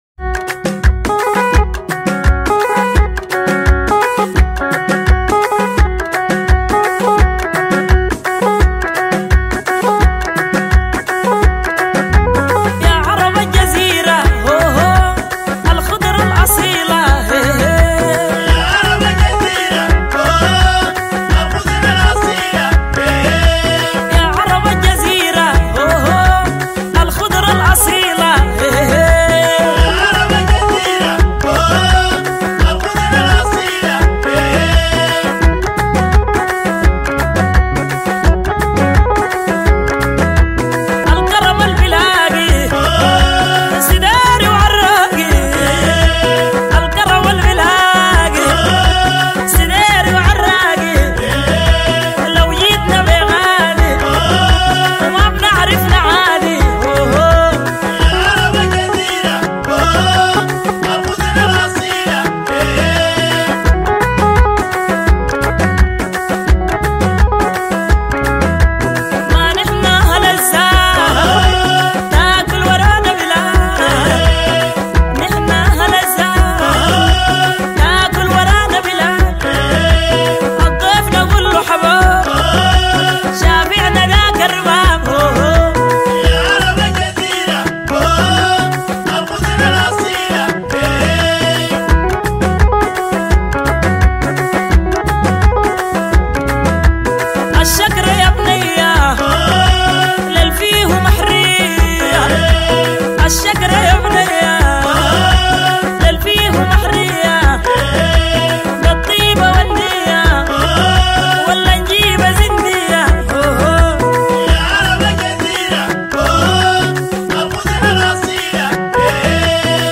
ربابة
بيز
كورال